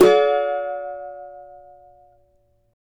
CAVA D#MJ  D.wav